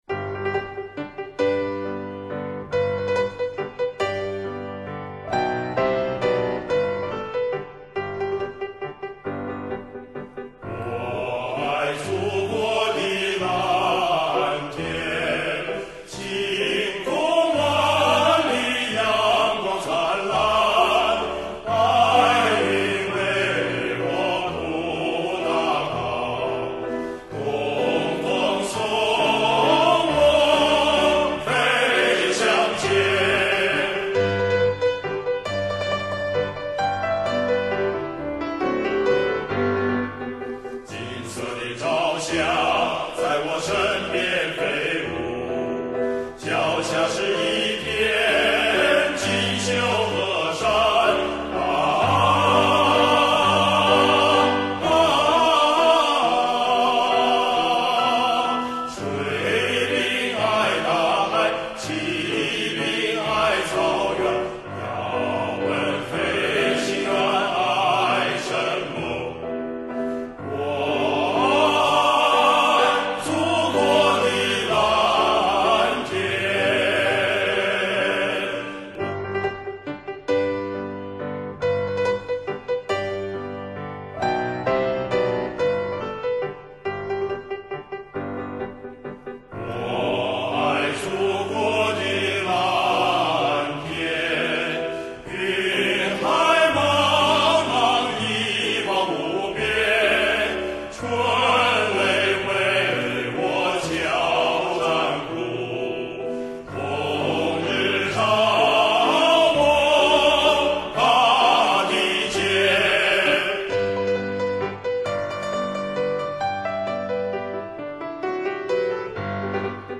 男声小合唱
因为它是一首三拍子的歌曲，有点像圆舞曲，而且一句大调，一句小调，错落有致。